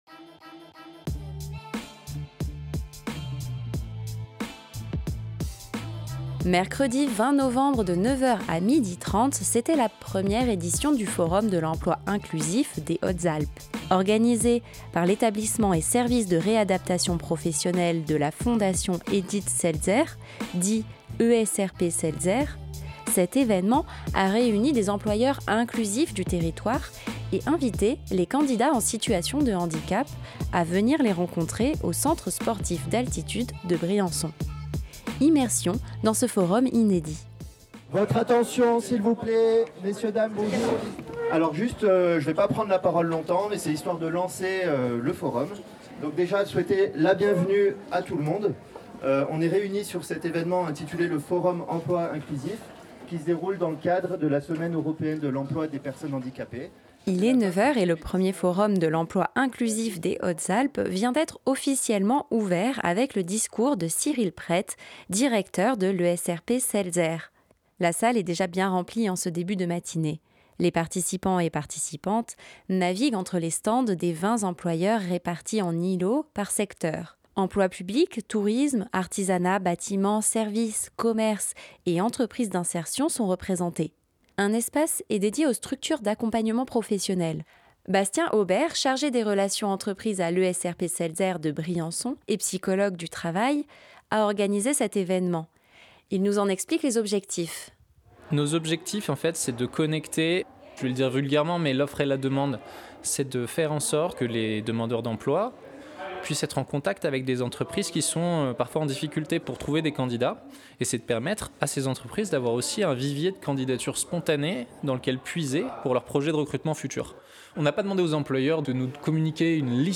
Organisé par l'ESRP Seltzer, cet événement a réuni des employeurs inclusifs du territoire et invité les candidats en situation de handicap à venir les rencontrer au Centre Sportif d'Altitude de Briançon. Immersion dans ce forum inédit.